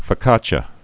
(fə-kächə, fō-)